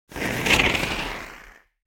دانلود آهنگ آتشفشان 9 از افکت صوتی طبیعت و محیط
دانلود صدای آتشفشان 9 از ساعد نیوز با لینک مستقیم و کیفیت بالا
جلوه های صوتی